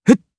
Siegfried-Vox_Jump_jp.wav